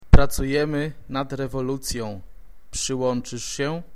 langsamere Antwort